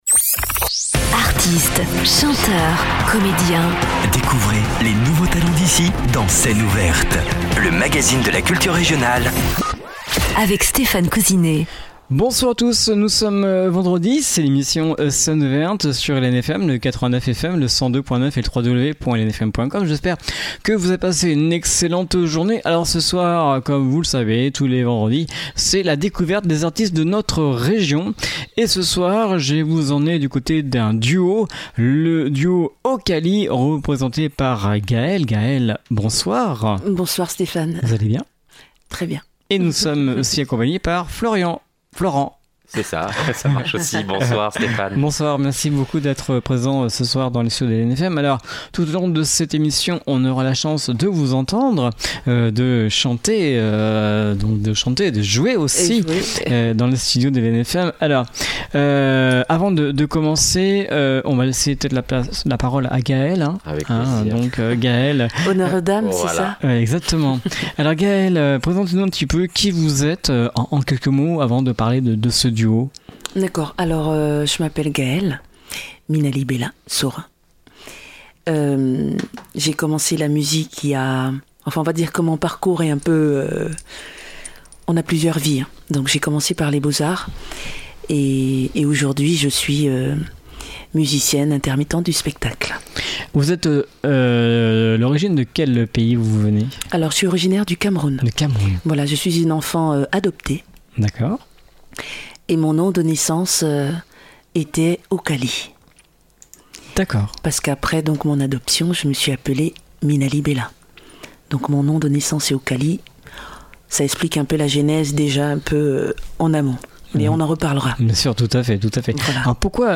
batterie